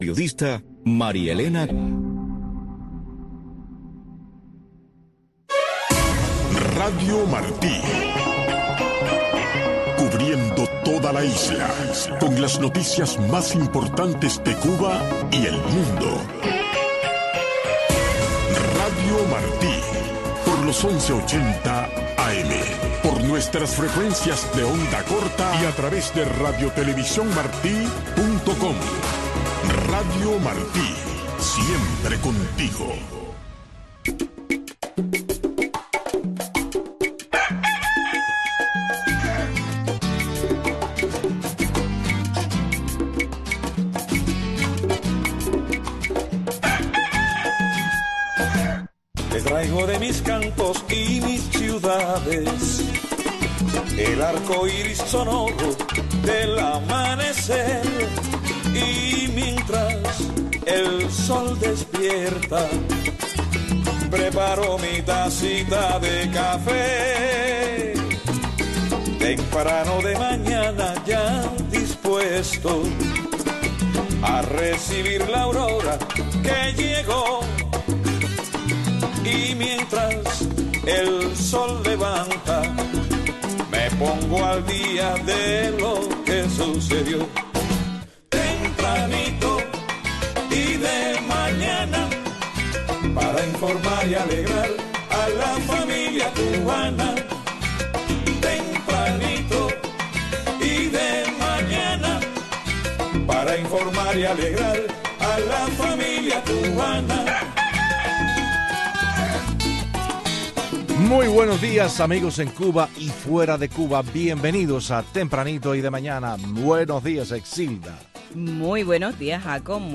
Disfrute el primer café de la mañana escuchando a Tempranito, una atinada combinación de noticiero y magazine, con los últimos acontecimientos que se producen en Cuba y el resto del mundo.